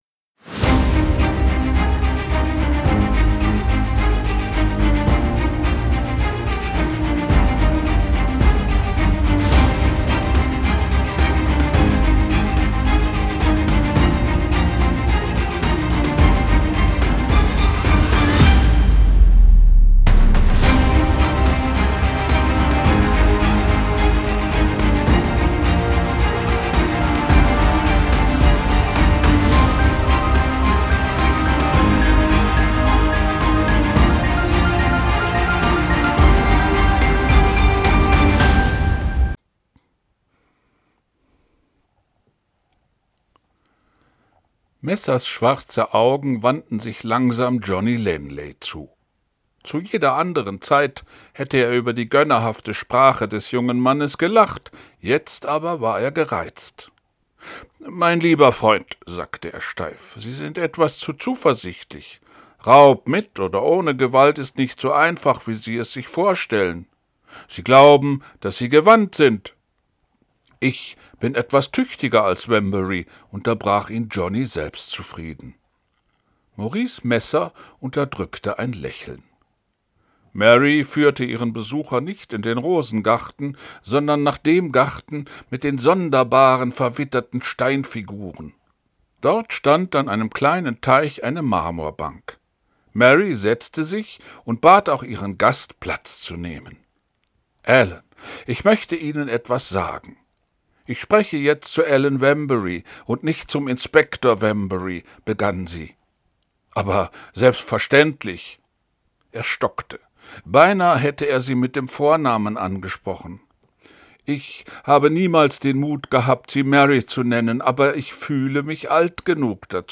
ich lese vor wallace hexer 4